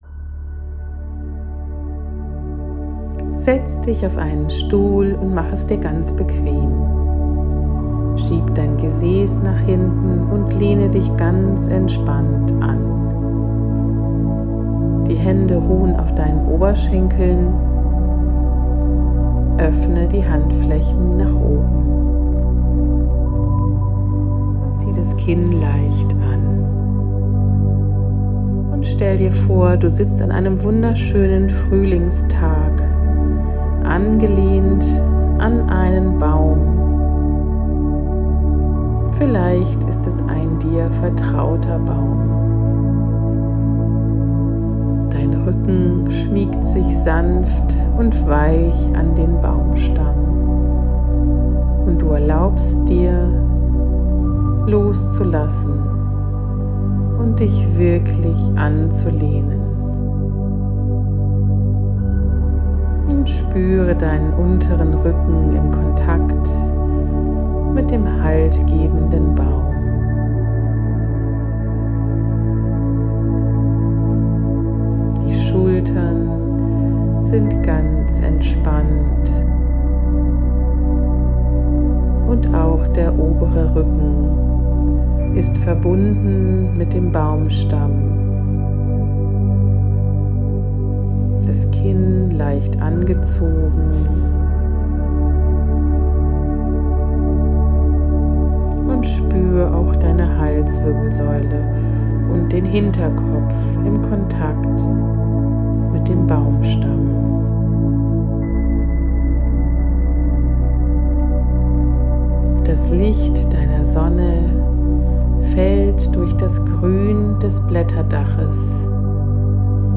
Selfcare-fuer-Hochsensible-Audiotrance.mp3